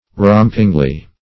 rompingly - definition of rompingly - synonyms, pronunciation, spelling from Free Dictionary Search Result for " rompingly" : The Collaborative International Dictionary of English v.0.48: Rompingly \Romp"ing*ly\, adv.